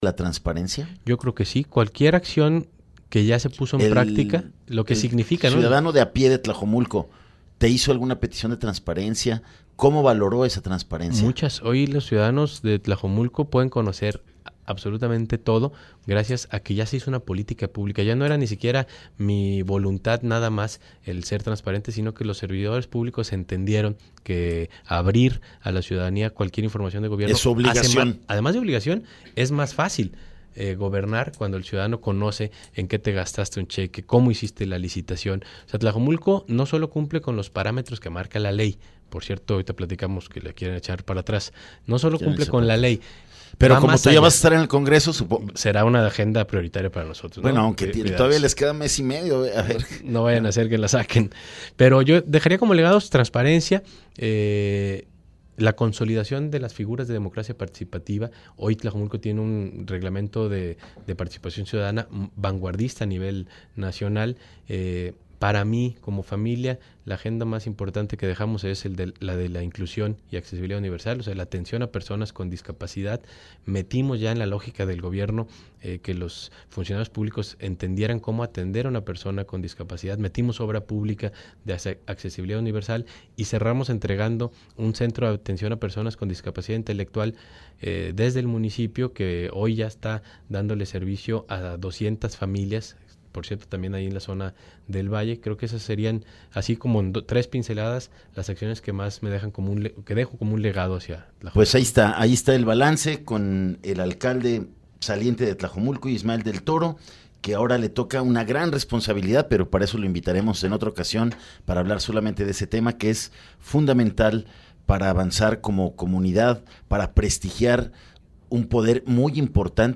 ENTREVISTA 100915